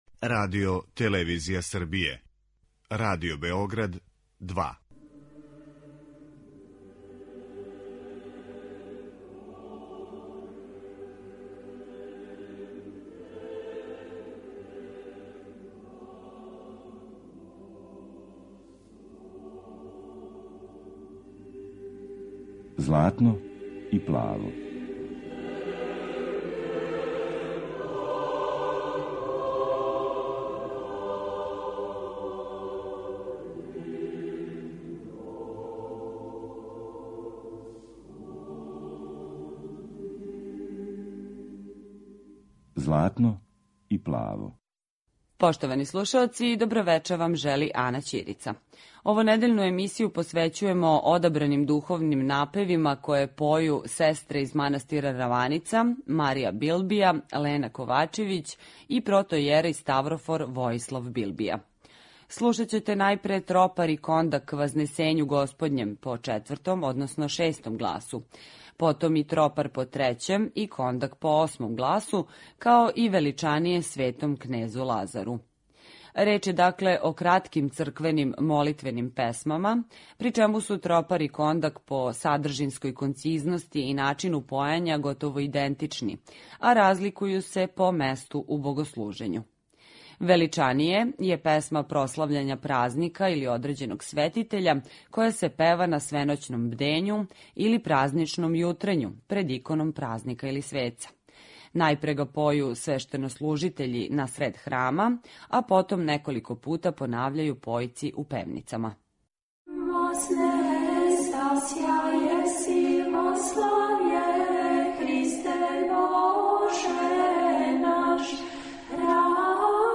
Емисија о православној духовној музици
Духовни напеви и појање сестара манастира Раваница
Ове суботе слушаћете одабране духовне напеве које поју сестре манастира Раваница.